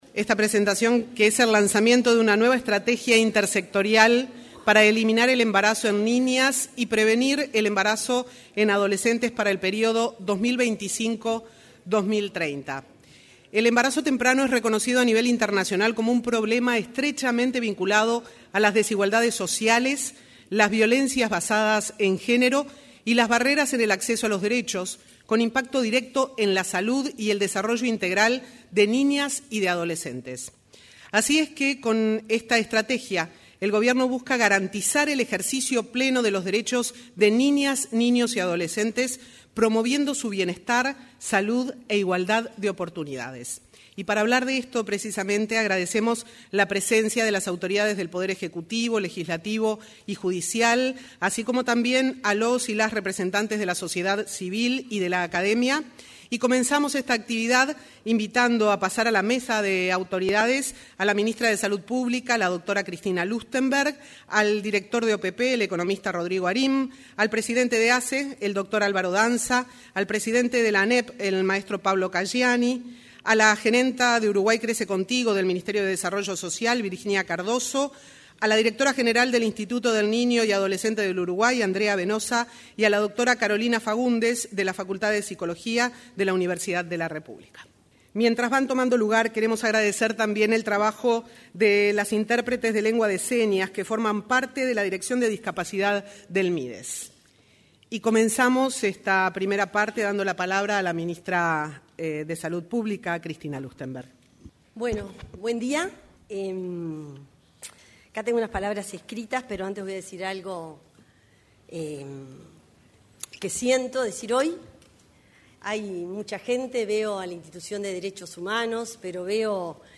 Lanzamiento de estrategia intersectorial para prevenir embarazos en niñas y adolescentes 13/11/2025 Compartir Facebook X Copiar enlace WhatsApp LinkedIn En el auditorio de la Torre Ejecutiva se realizó el lanzamiento de una estrategia intersectorial para la eliminación de embarazos en niñas y la prevención en adolescentes. En la oportunidad, se expresó la ministra de Salud Pública, Cristina Lustemberg.